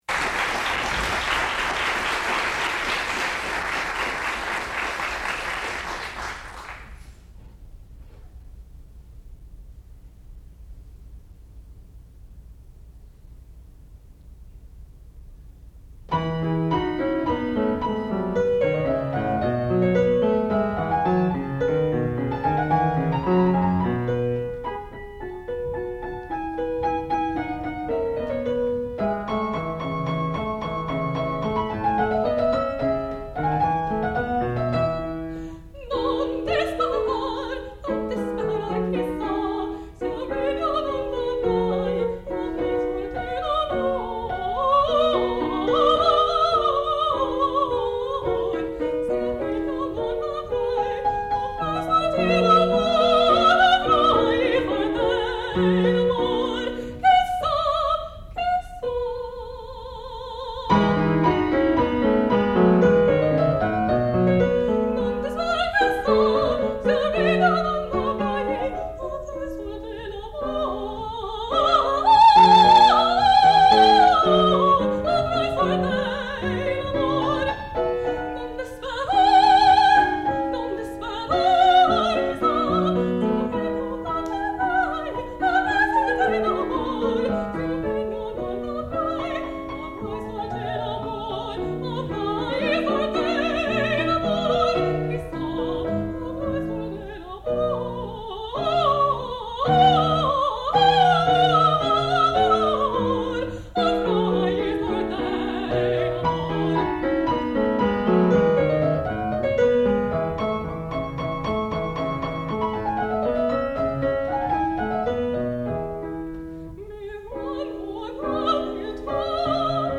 sound recording-musical
classical music
Qualifying Recital
soprano